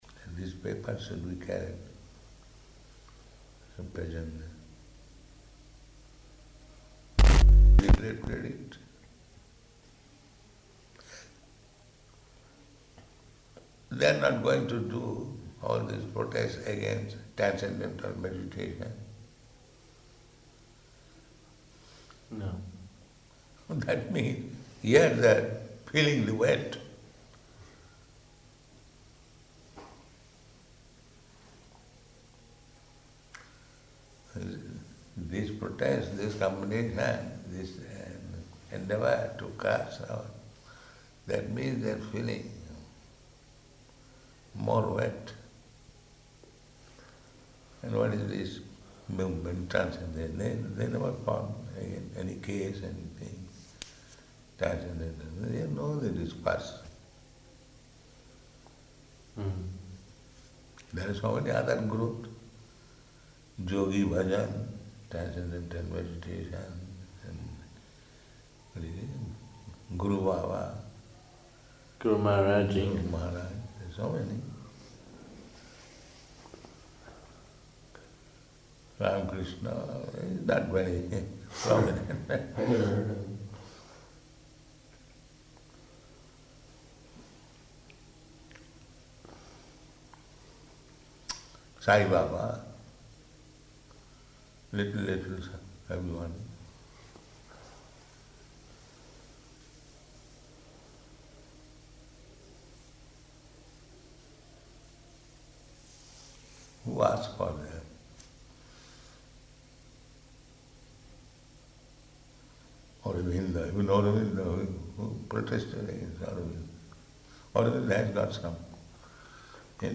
Room Conversation
Location: Vṛndāvana